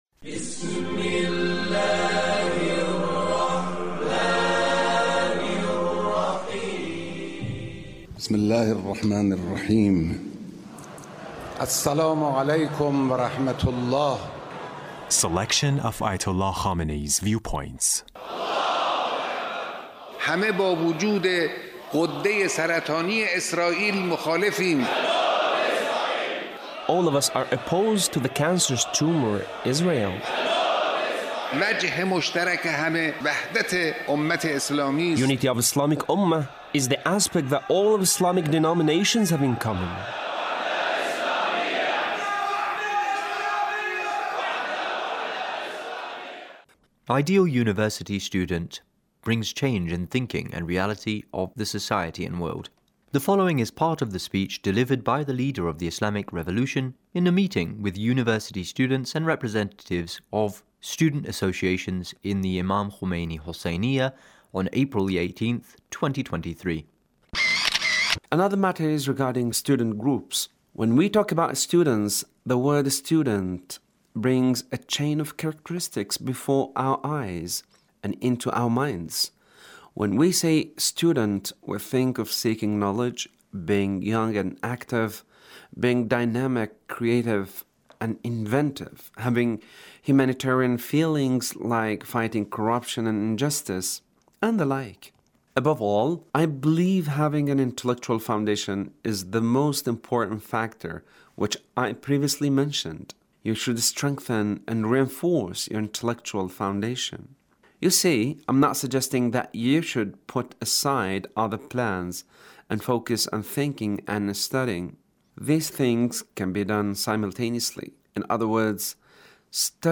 Leader's Speech (1712)
Leader's Speech with University Student